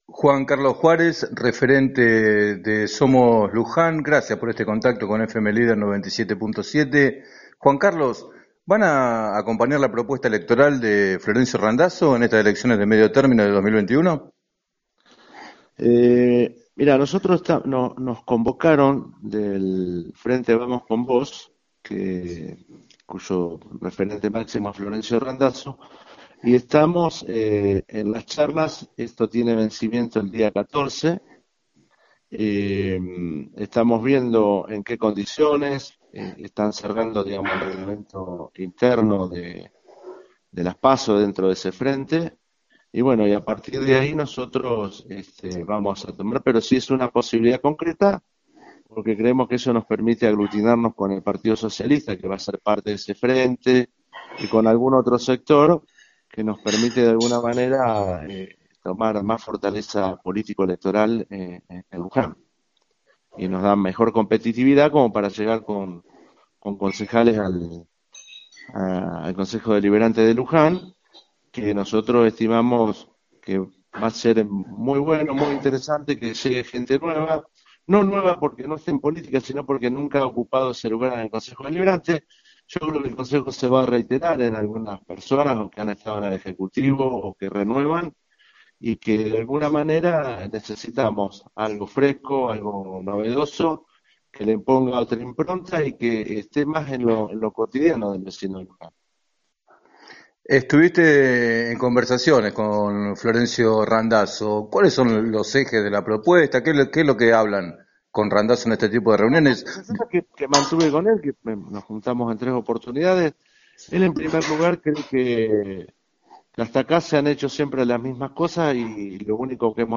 En declaraciones al programa “7 a 9” de FM Líder 97.7, Juárez contó que fueron convocados por el dirigente chivilcoyano, con quien ya se reunió en tres oportunidades, y que la incorporación al Frente es una posibilidad concreta para “lograr mayor competividad electoral y llegar al Concejo Deliberante con gente nueva”.